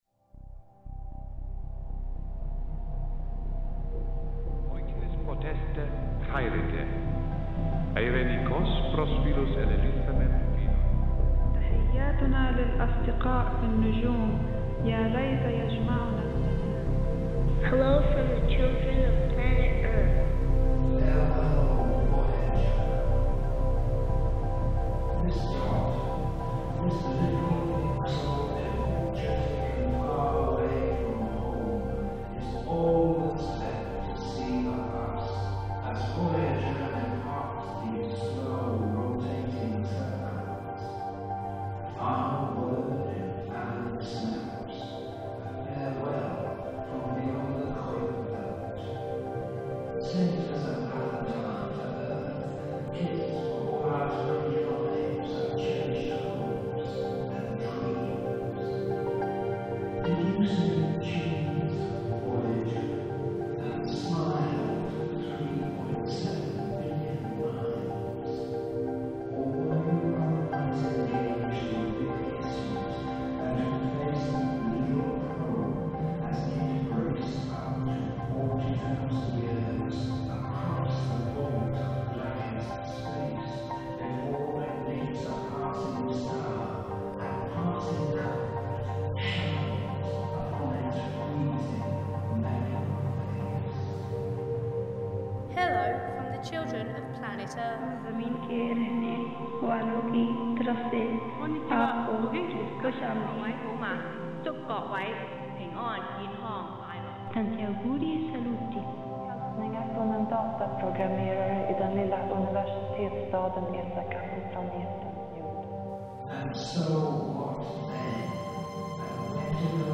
Farewell, Voyager [with NASA multinational children's voice greetings to aliens
soundscape